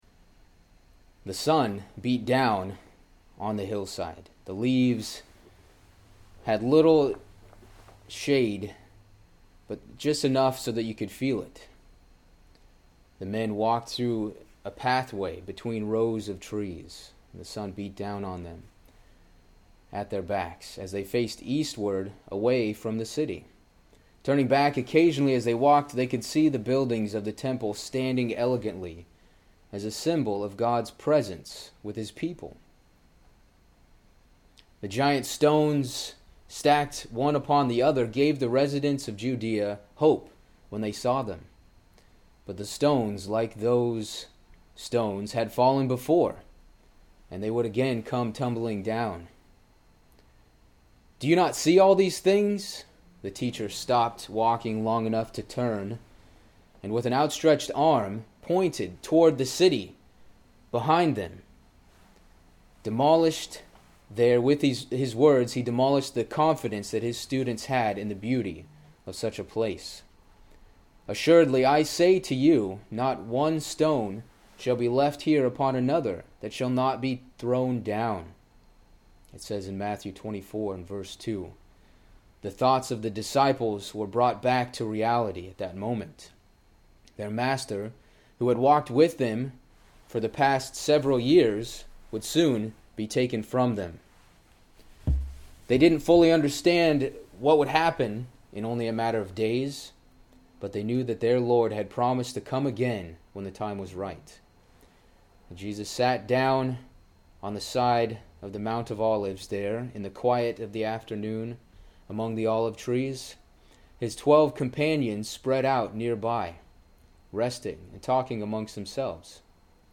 This sermon is the third in a series based on the letters to the congregations found in the book of Revelation.